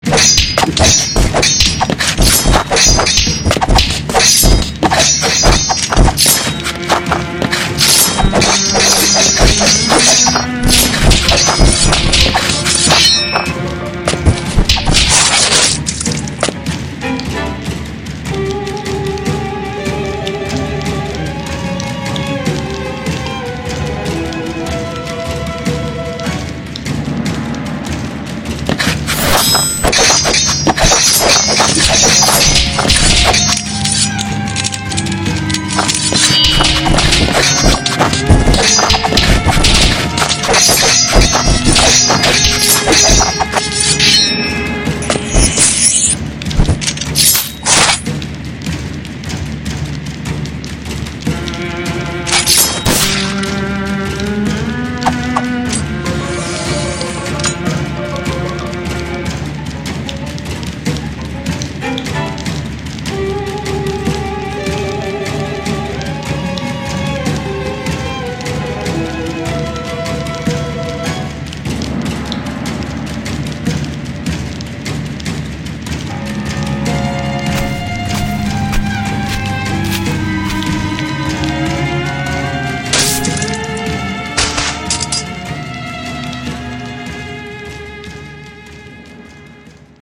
【和風/忍者/戦闘/声劇台本】